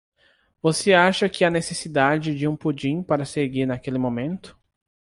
Pronounced as (IPA) /puˈd͡ʒĩ/